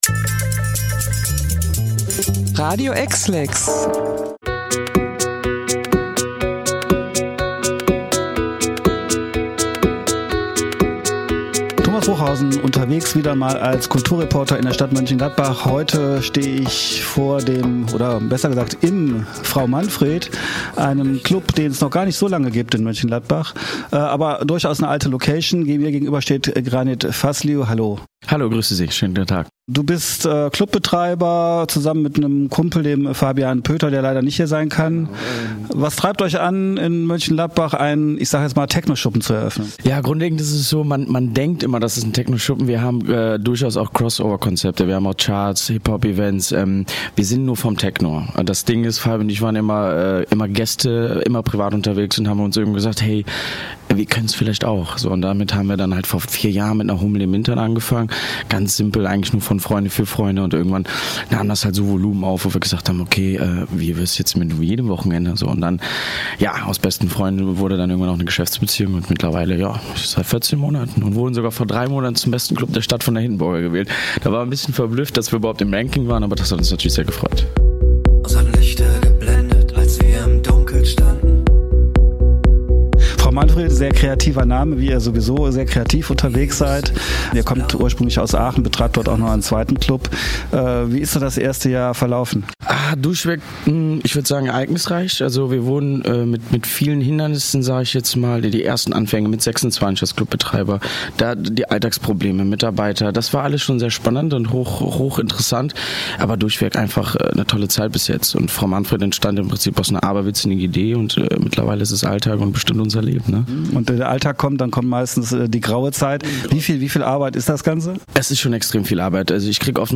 getroffen und interviewt.